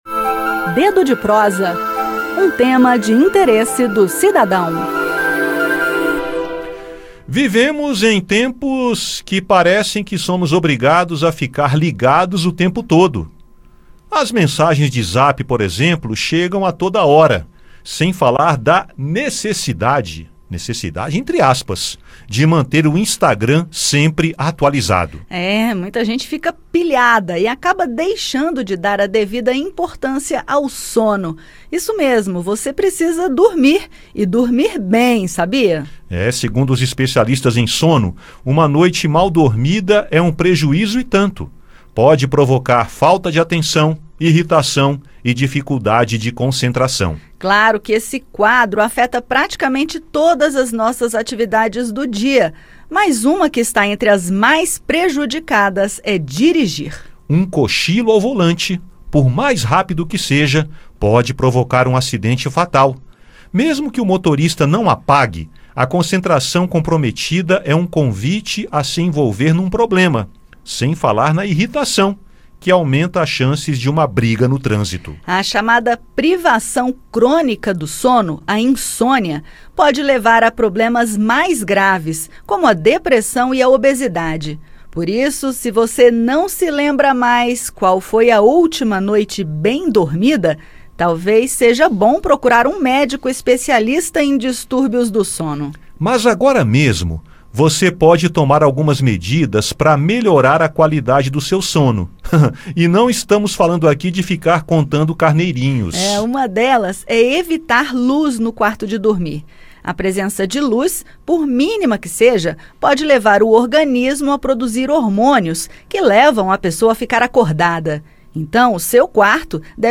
Programa diário com reportagens, entrevistas e prestação de serviços